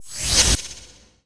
rogue_skill_dash_attack_start.wav